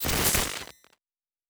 pgs/Assets/Audio/Sci-Fi Sounds/Electric/Glitch 2_04.wav at master
Glitch 2_04.wav